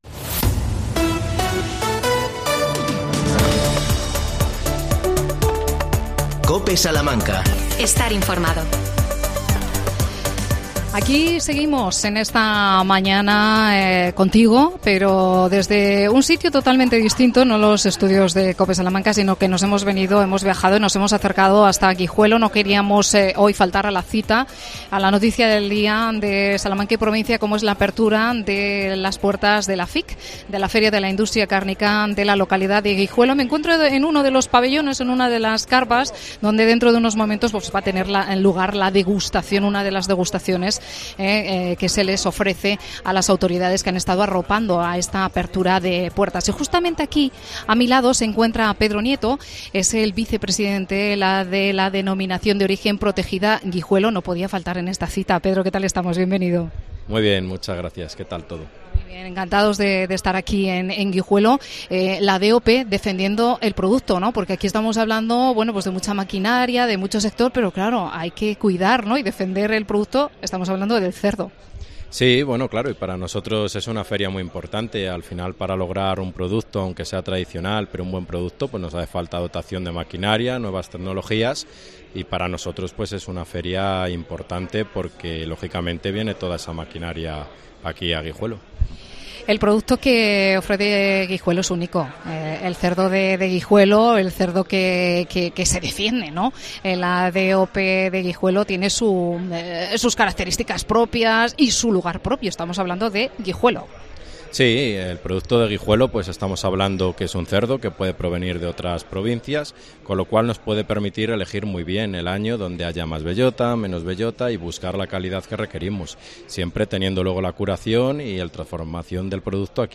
AUDIO: Programa especial desde Guijuelo, Inauguración FIC .